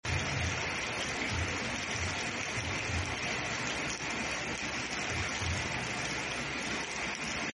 suara hujan menenangkan untuk relaksasi